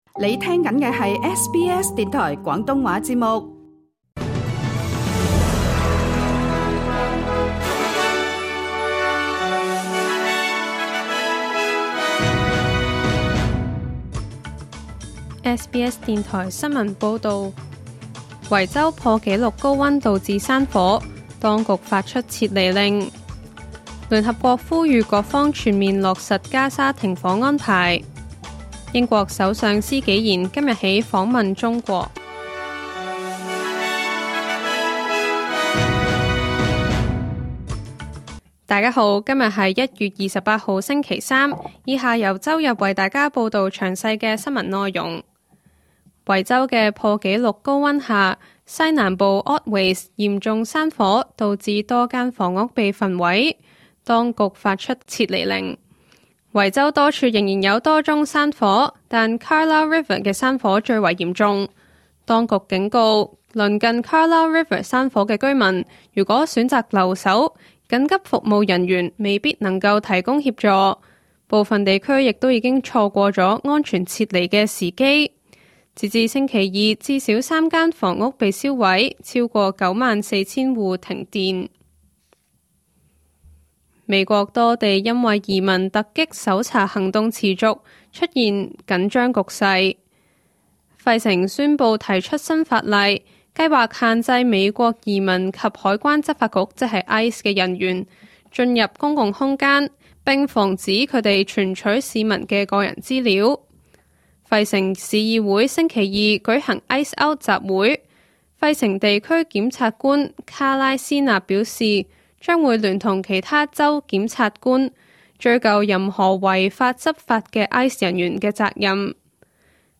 2026 年 1 月 28 日 SBS 廣東話節目詳盡早晨新聞報道。